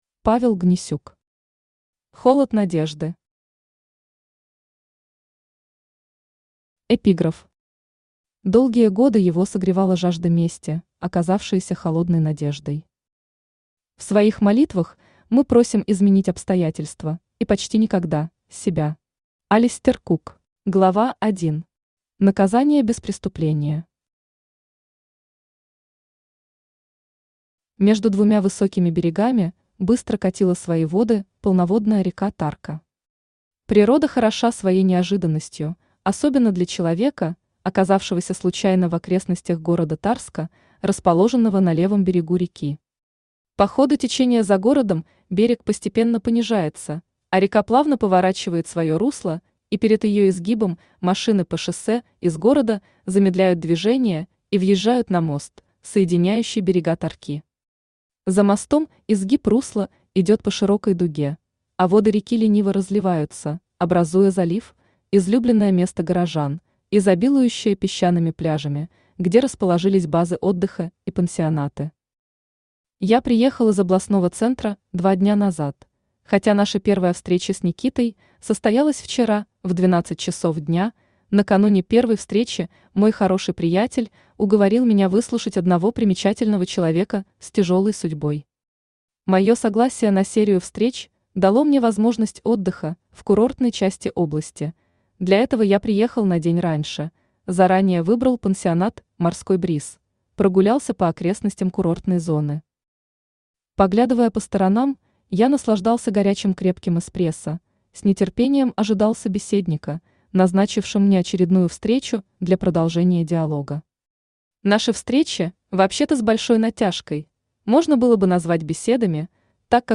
Аудиокнига Холод надежды | Библиотека аудиокниг
Aудиокнига Холод надежды Автор Павел Борисович Гнесюк Читает аудиокнигу Авточтец ЛитРес.